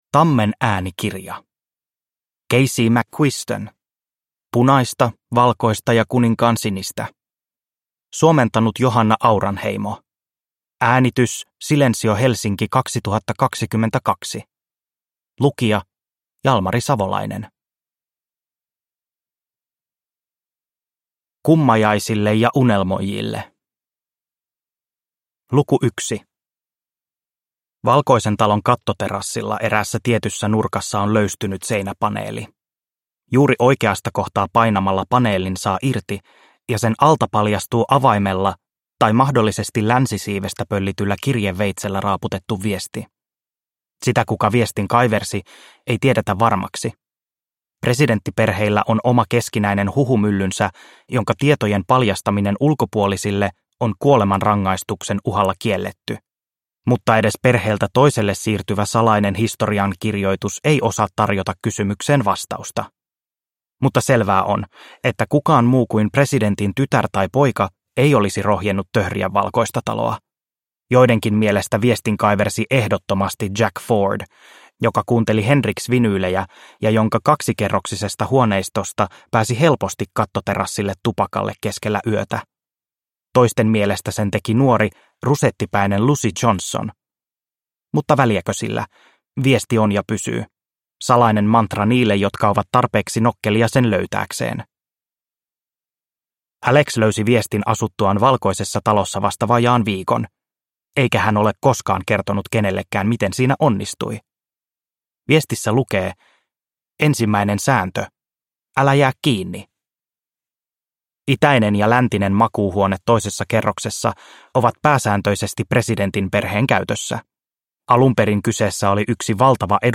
Punaista, valkoista ja kuninkaansinistä – Ljudbok – Laddas ner